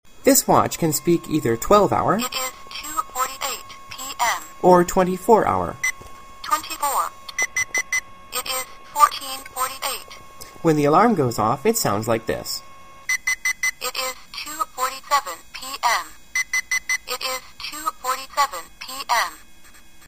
Affordable Tried-and-True Model with Clear Voice
• Boasts clear female voice, and large digital LCD display.
Whenever you tap the button on the side of this talking watch, a clear female voice will read the time to you.
classic_talking_watch.mp3